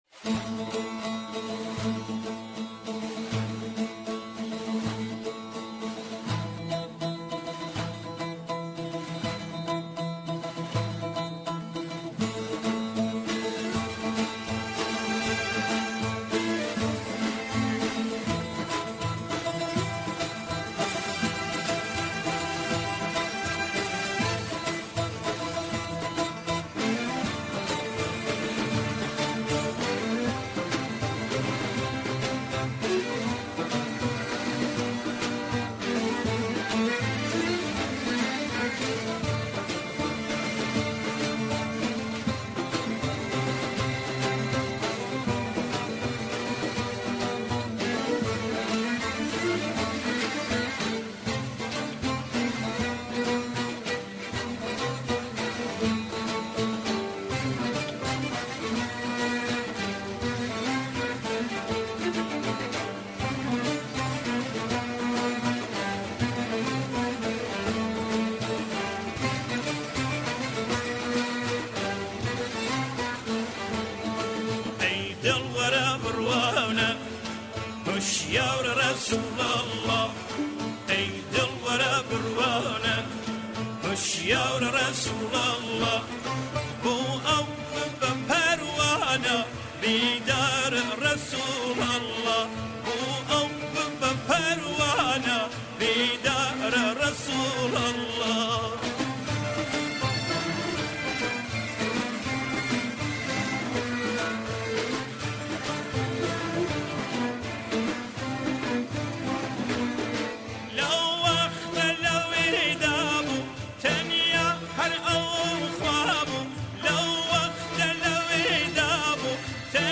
گۆرانیێکی خۆشی کوردی به ناوی یارسول الله (ص)